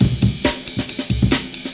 In the “4_oscillators_wavetables” directory, there is a shortened and down-sampled version of the famous “Amen break” drumloop, called
It’s been down-sampled to 8kHz sample rate and lasts for 1.75 seconds.
amen1_8k_s16.wav